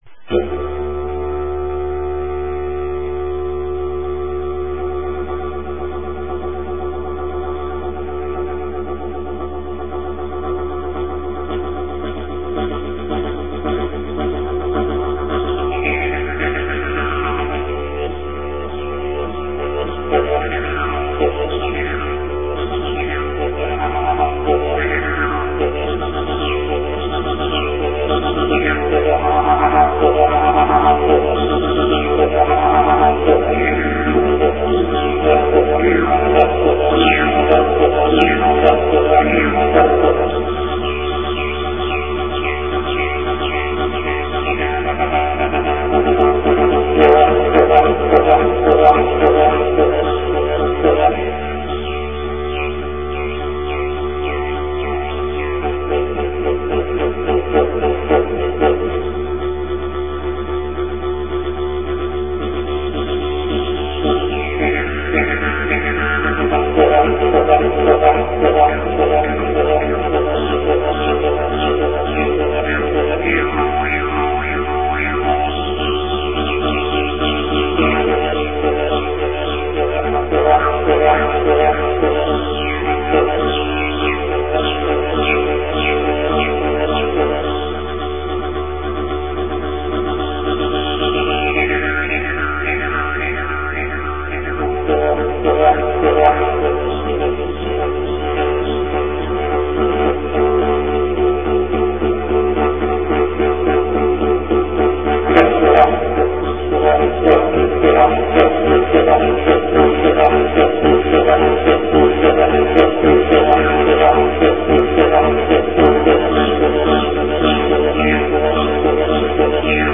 Traditional Didjeridu